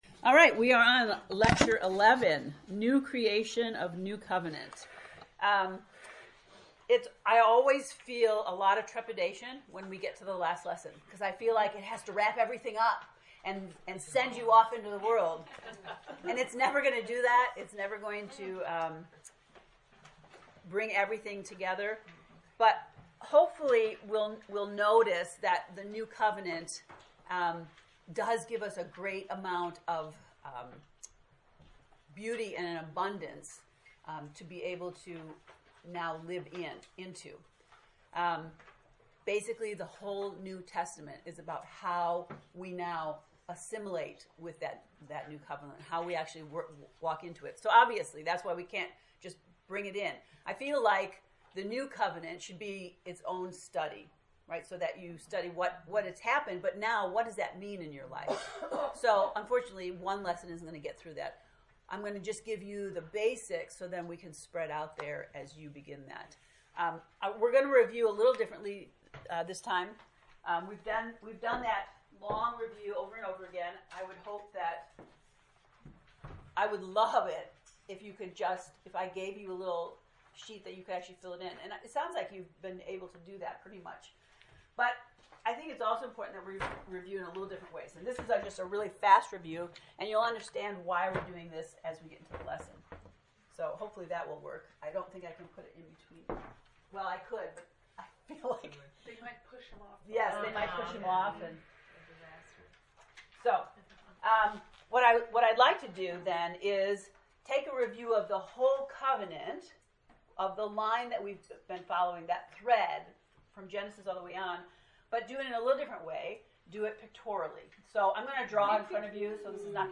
COVENANT lecture 11